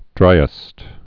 (drīĭst)